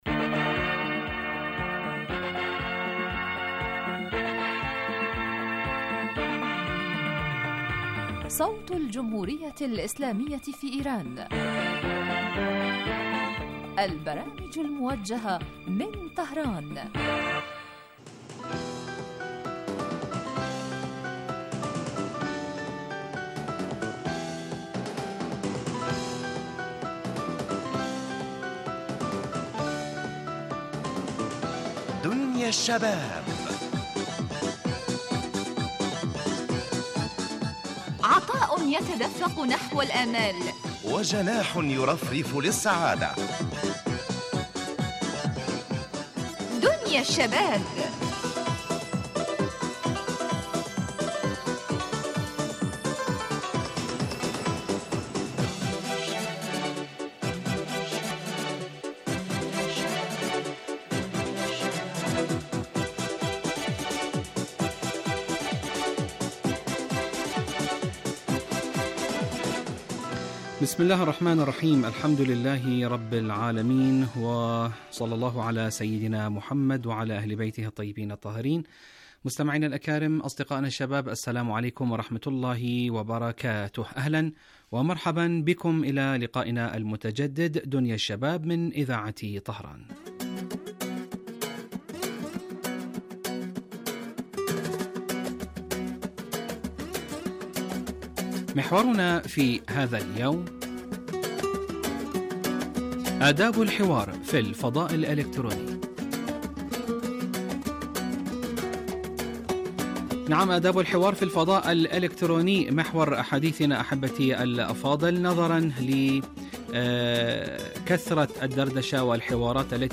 برنامج اجتماعي غني بما يستهوي الشباب من البلدان العربية من مواضيع مجدية و منوعة و خاصة ما يتعلق بقضاياهم الاجتماعية وهواجسهم بالتحليل والدراسة مباشرة علي الهواء.